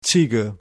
Ääntäminen
Synonyymit sik Ääntäminen Tuntematon aksentti: IPA: /ɣɛi̯t/ Haettu sana löytyi näillä lähdekielillä: hollanti Käännös Ääninäyte 1.